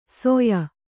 ♪ soya